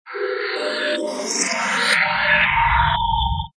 Efectos de sonido